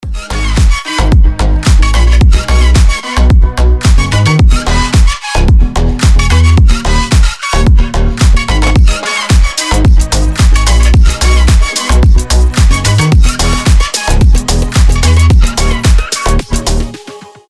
• Качество: 320, Stereo
громкие
EDM
без слов
future house
Фьюче-хаус с неким этническим мотивом